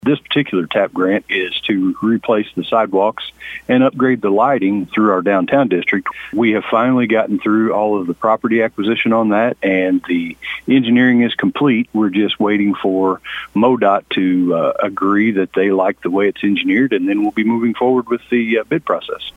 City Administrator of Bonne Terre, Shawn Kay, explains the money is coming from a Transportation Alternative Program grant.